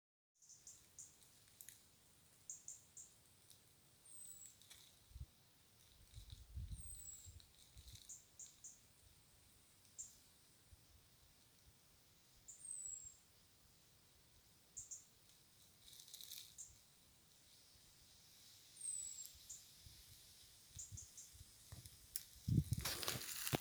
Birds -> Thrushes ->
European Robin, Erithacus rubecula
StatusAgitated behaviour or anxiety calls from adults